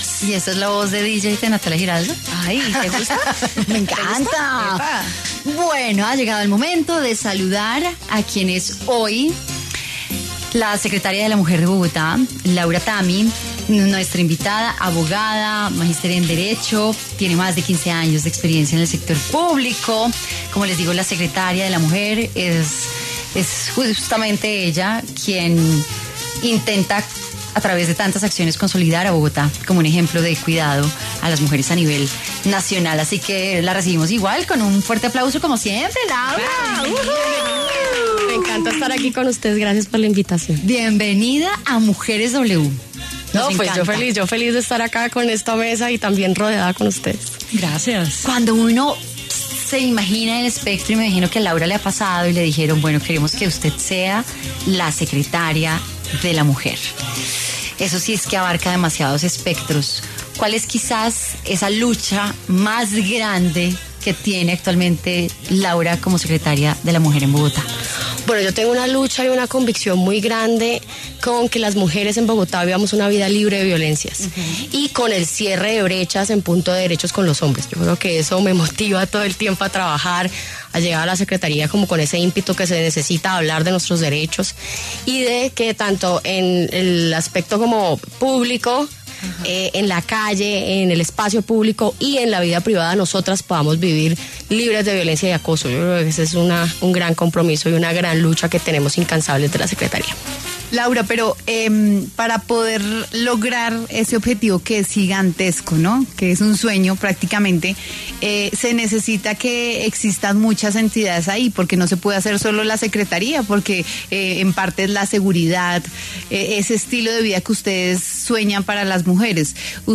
La secretaria de la Mujer de Bogotá, Laura Tami, habló en Mujeres W sobre las iniciativas que están implementando para mejorar la calidad de vida de las ciudadanas y lo que la impulsa para trabajar en pro de las mujeres.